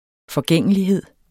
Udtale [ fʌˈgεŋˀəliˌheðˀ ]